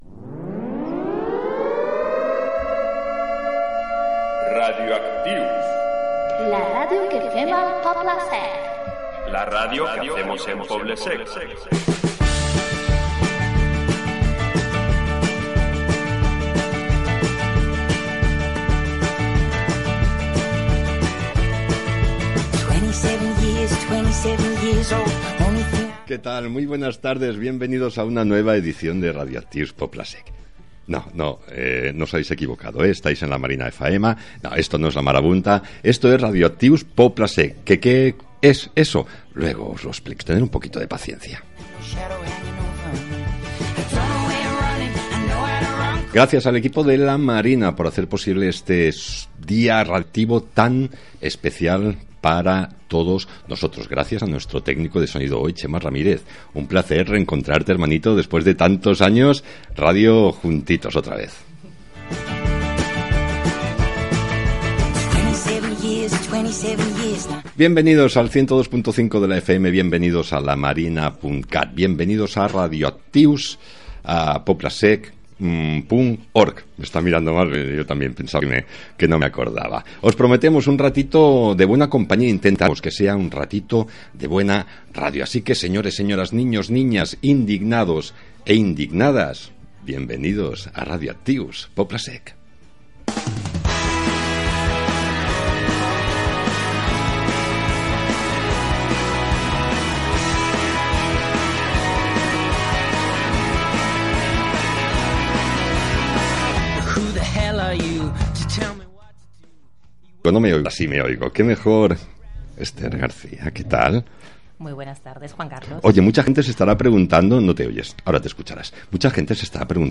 Careta del programa
Info-entreteniment